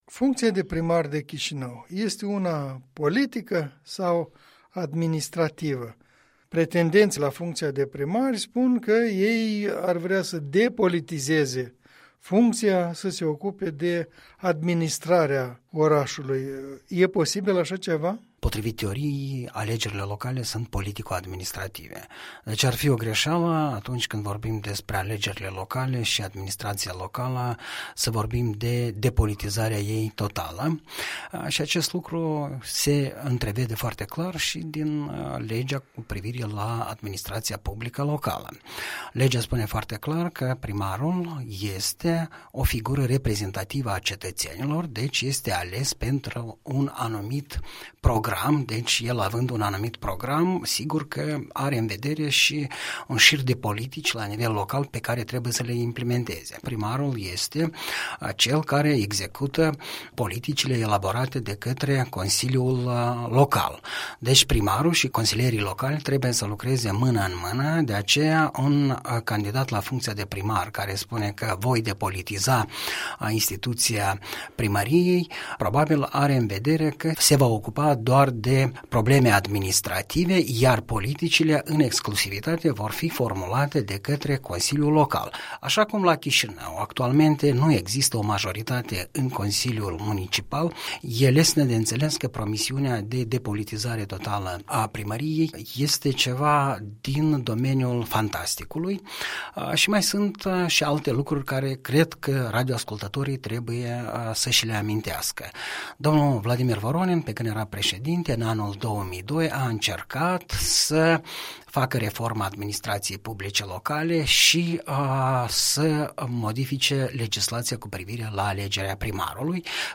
Un punct de vedere săptămânal în dialog despre limita administraţiei publice şi a politicului.